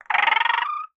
vox_chitter.ogg